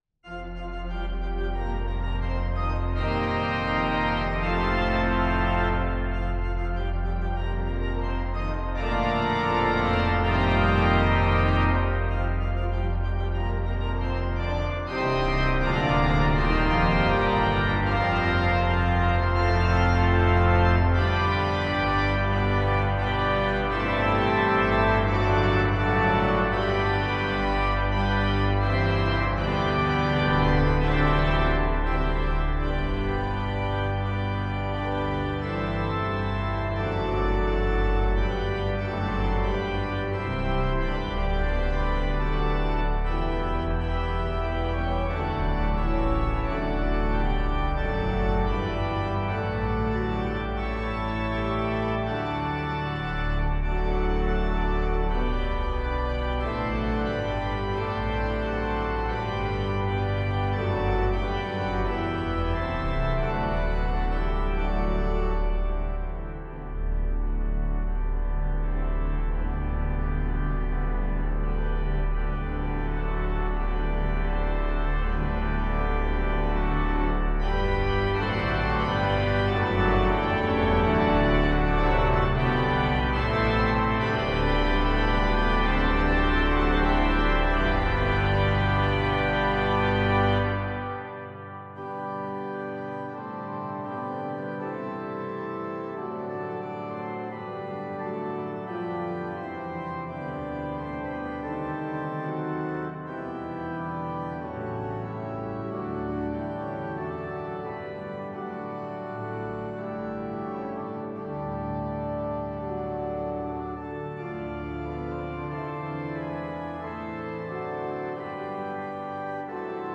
Voicing: Organ